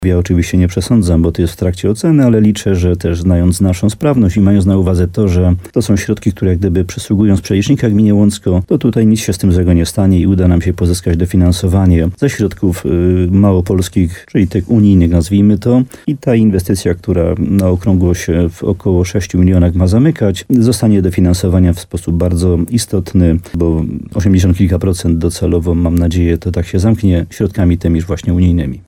Jak powiedział w programie Słowo za Słowo w radiu RDN Nowy Sącz wójt gminy Łącko Jan Dziedzina, samorząd czeka na rozpatrzenie wniosku o duże dofinansowanie z funduszy europejskich w ramach Sądeckiego Obszaru Funkcjonalnego.